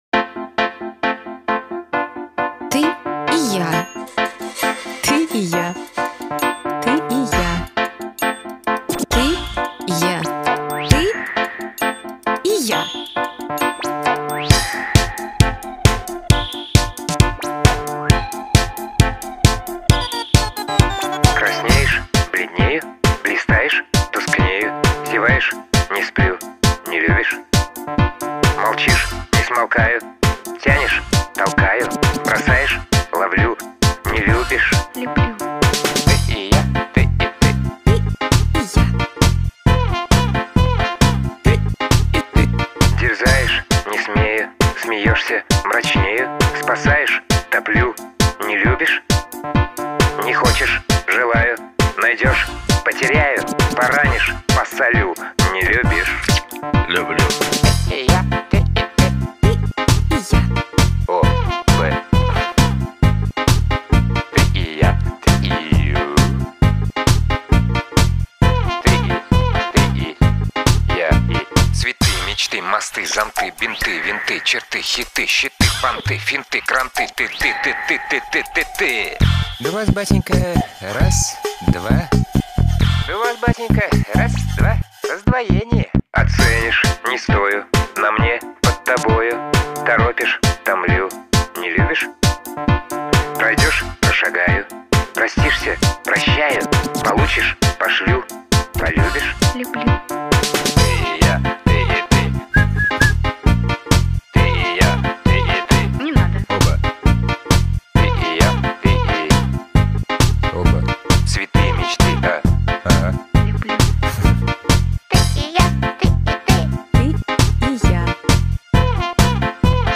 Русские песни
• Качество: 320 kbps, Stereo